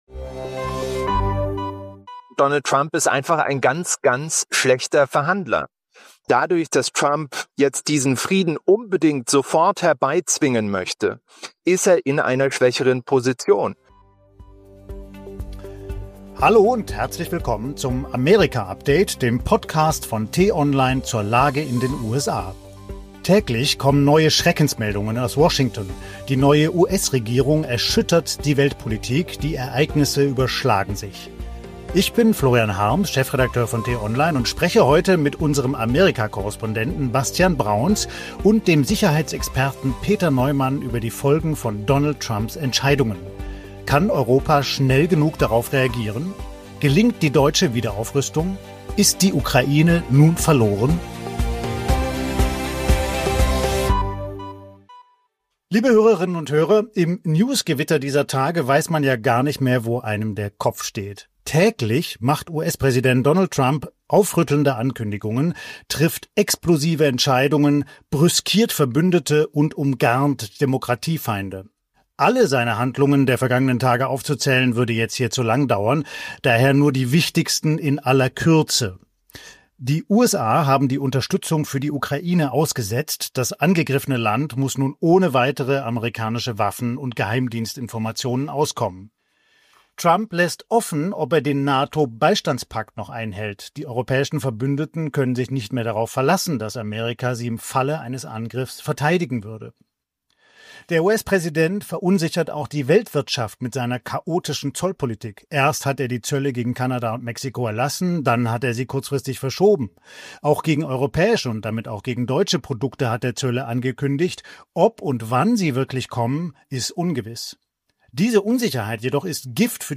Was hinter seiner Strategie steckt und warum sich Trump in einer schwächeren Position gegenüber Putin befindet, erklärt Neumann in dieser Folge.